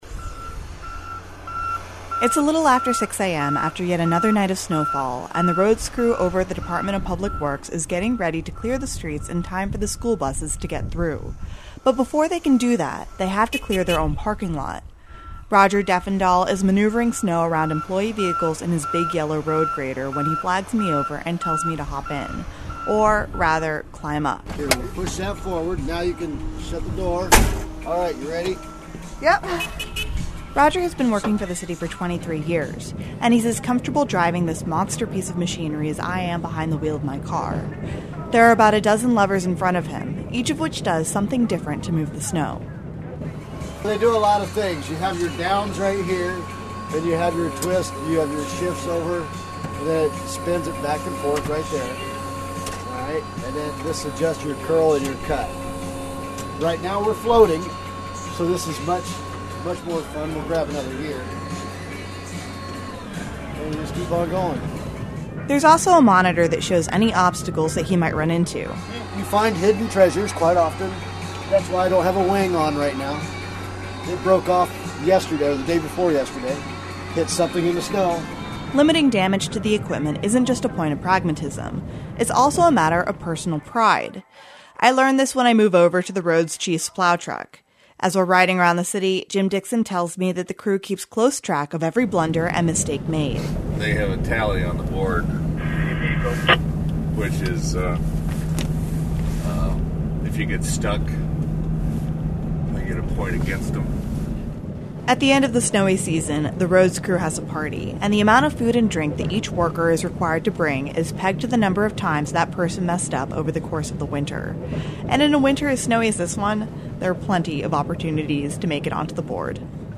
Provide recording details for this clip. went out with the roads crew one frosty morning to find out what exactly goes into that process.